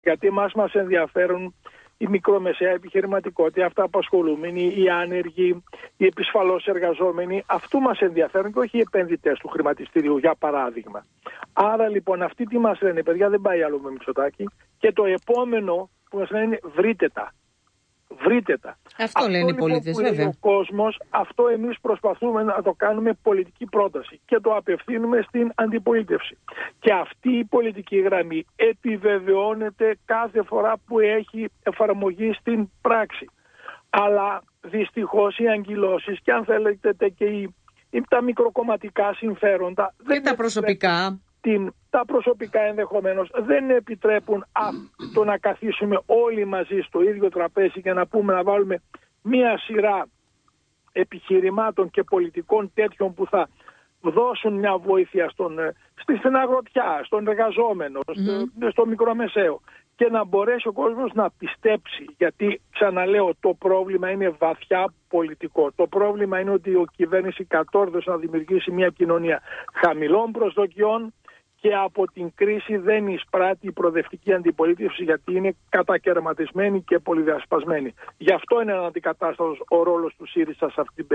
Βαθιά ταξικό και κοινωνικά άδικο χαρακτήρισε τον προϋπολογισμό του 2026 ο βουλευτής Δράμας και Τομεάρχης Εσωτερικών Θ. Ξανθόπουλος, μιλώντας στο Κόκκινο.